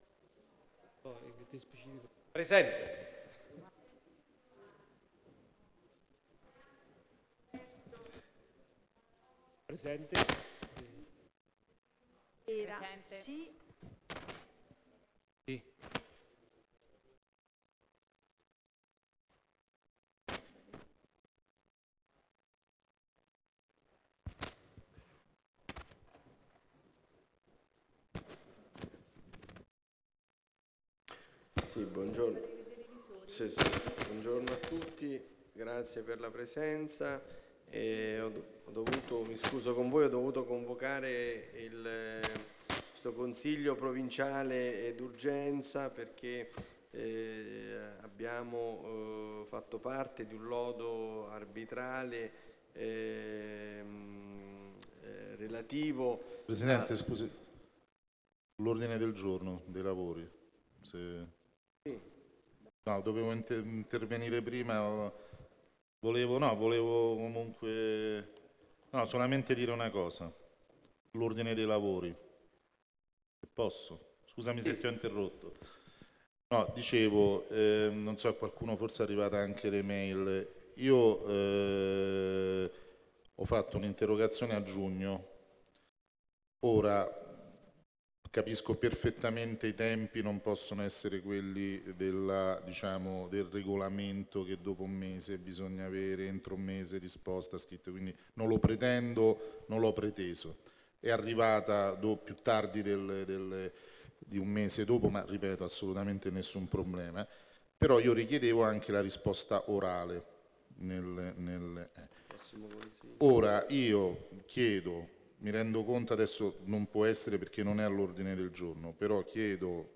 Seduta del consiglio del 29 Settembre 2017